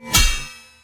melee-hit-8.ogg